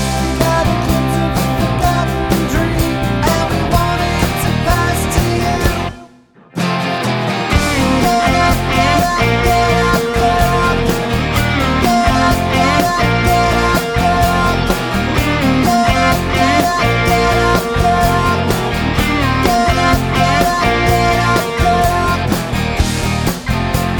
no Backing Vocals Indie / Alternative 3:57 Buy £1.50